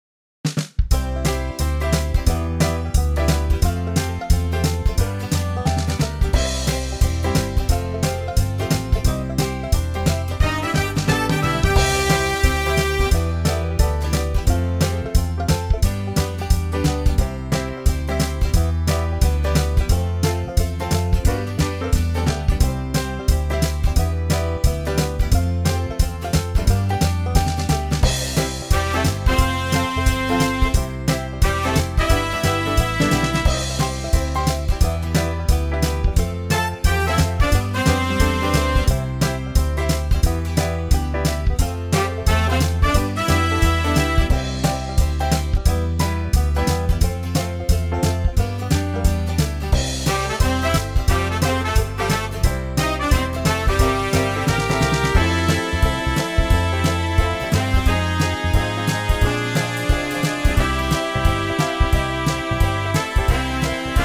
HARMONICA AND SOLO REMOVED
MP3 NO SOLO DEMO:
key - C - vocal range - A to A